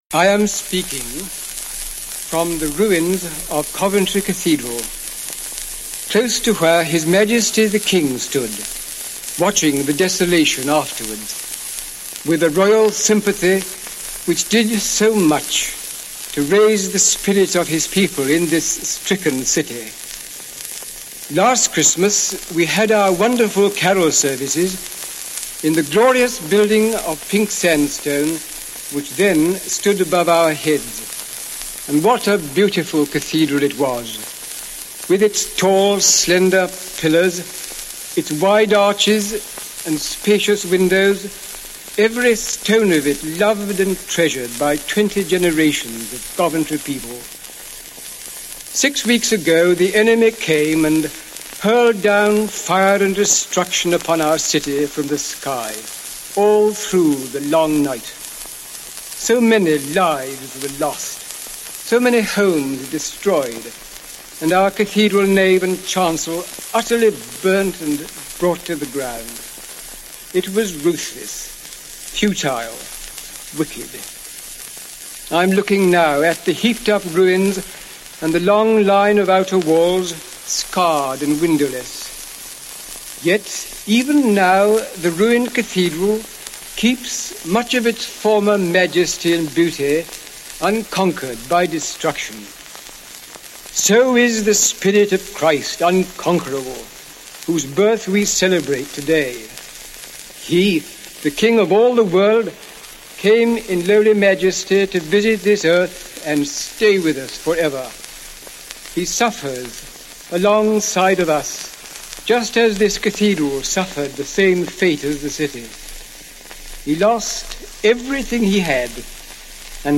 From Provost Howard’s 1940 broadcast, made from the Ruins on Christmas Day, six weeks after the Blitz.
Original BBC recording